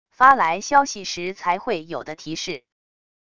发来消息时才会有的提示wav音频